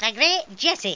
Amiga 8-bit Sampled Voice
1 channel
coward.mp3